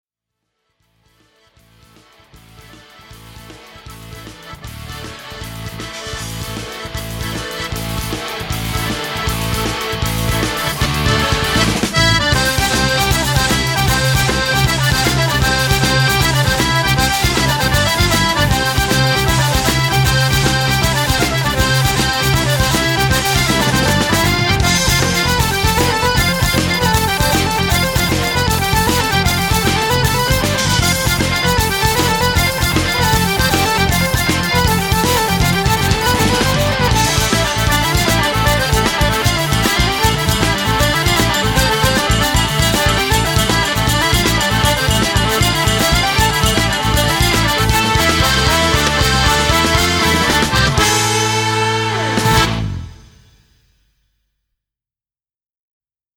(instr.)